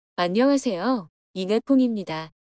단순 소리 크기의 비교를 위한 오디오 입니다.
+20dB
dB-비교-음원_20dB.mp3